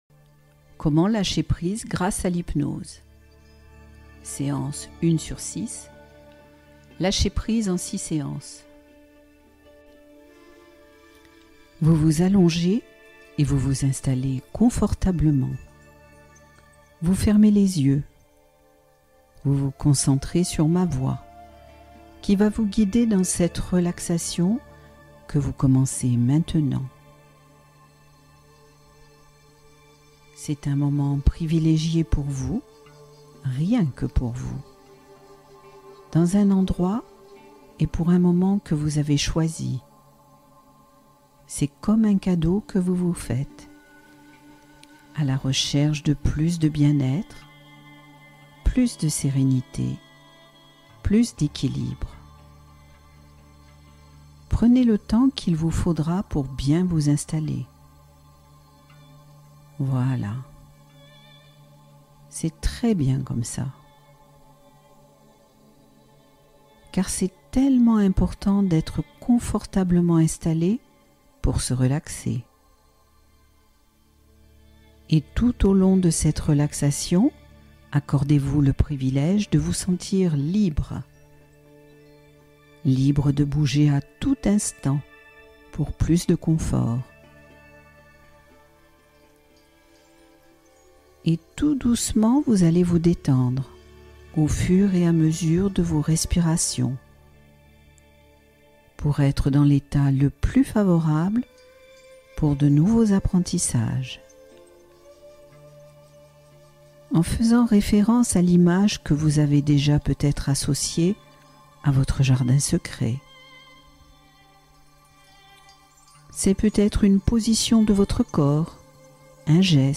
Gérer ses émotions : séance guidée d’apaisement